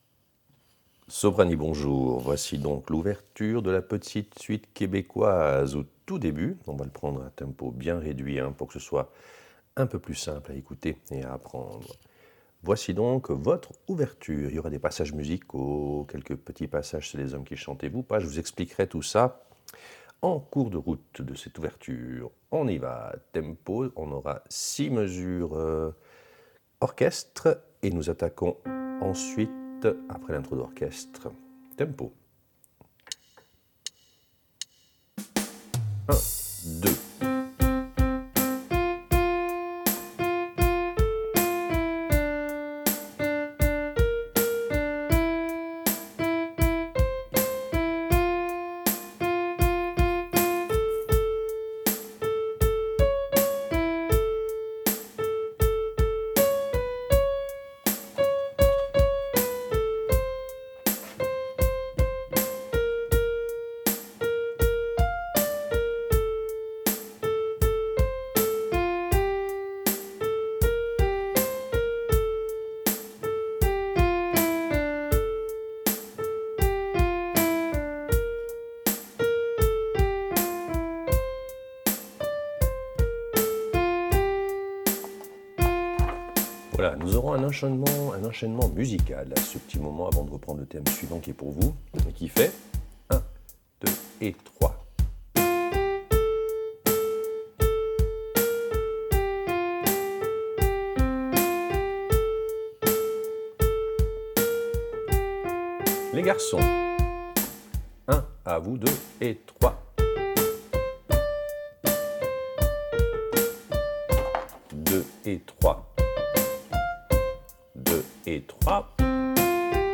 Répétition SATB4 par voix
Soprano